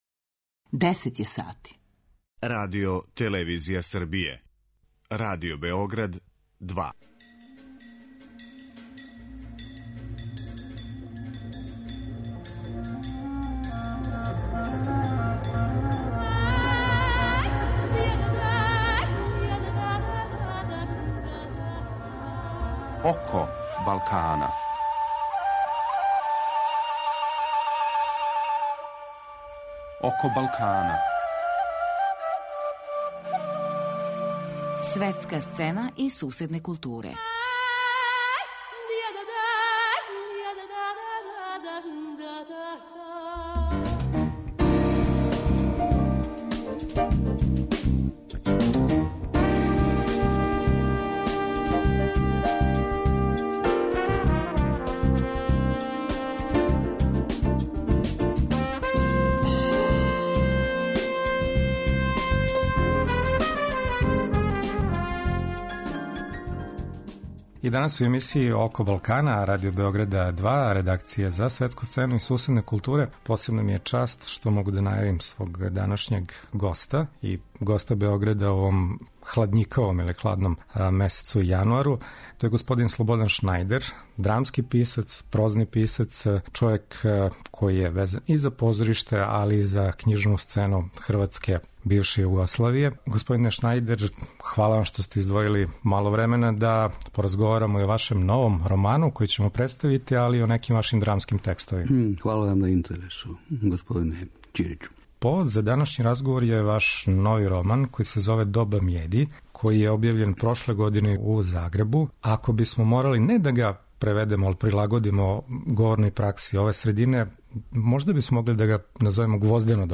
Гост емисије је Слободан Шнајдер, хрватски драмски и прозни писац.